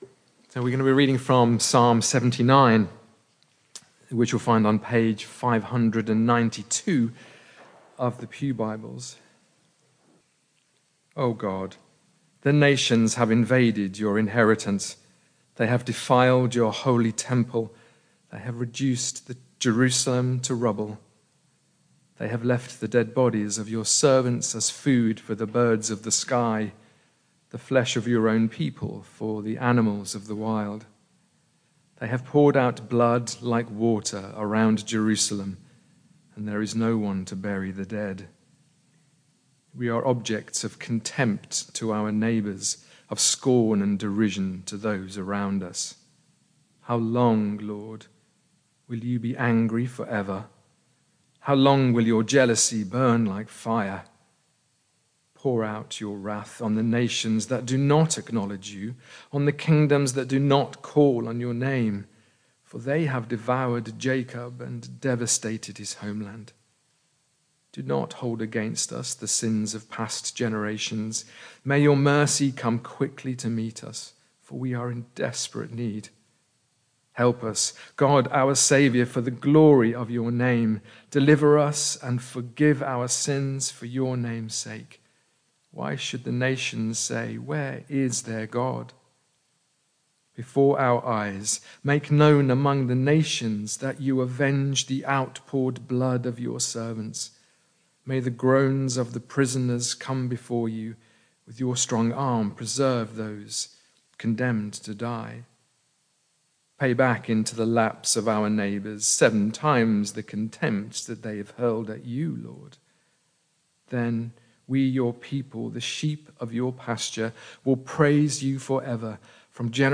Barkham Morning Service
Reading and sermon